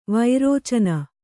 ♪ vairōcana